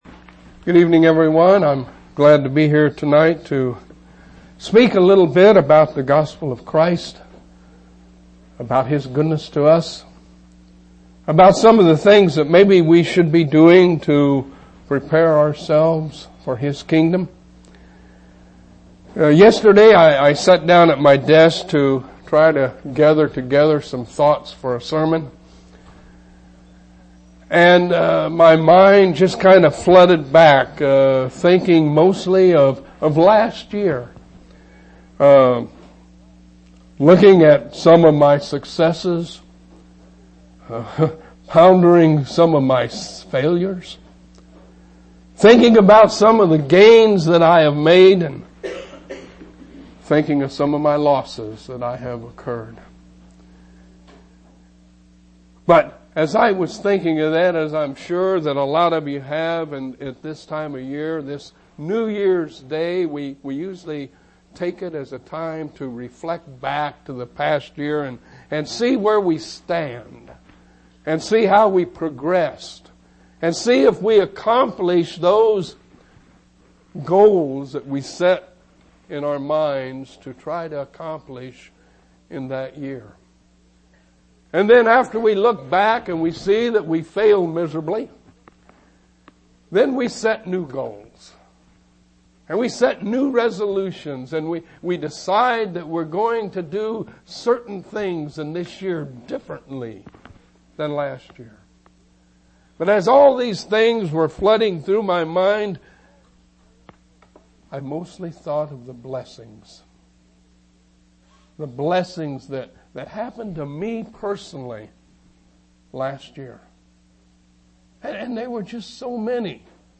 1/2/2000 Location: East Independence Local Event